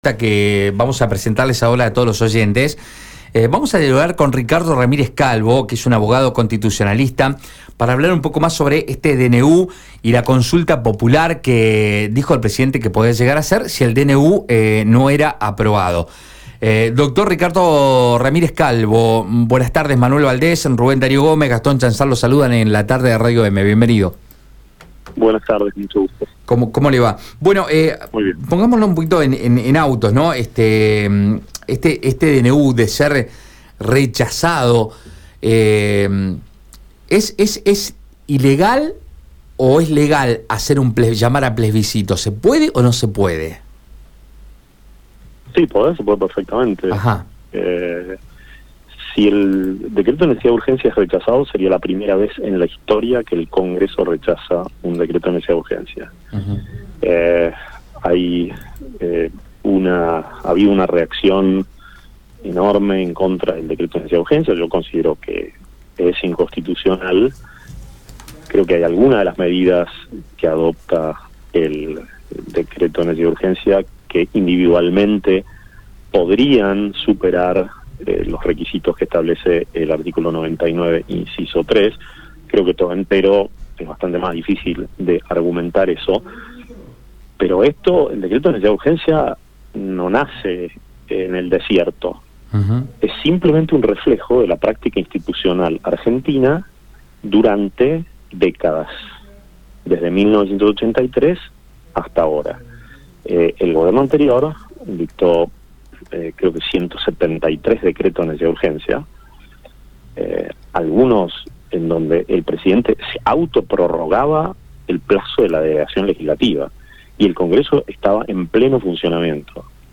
abogado constitucionalista.